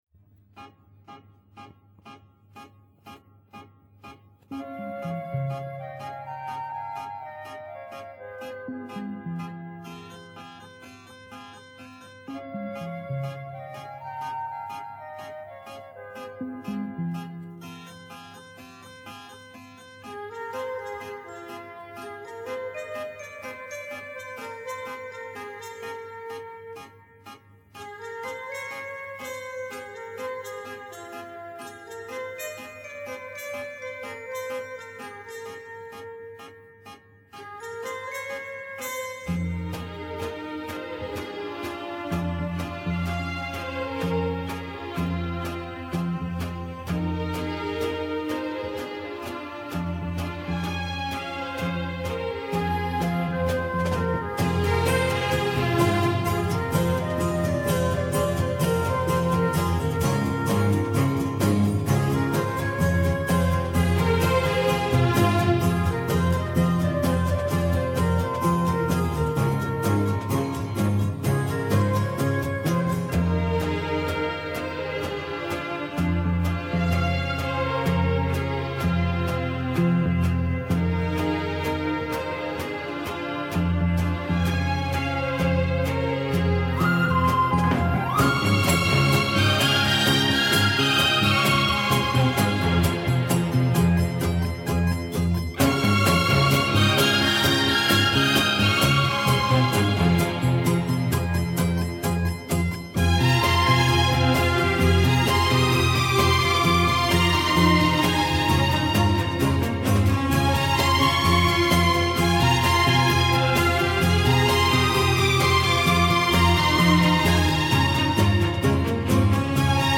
Genre:Instrumental,Easy Listening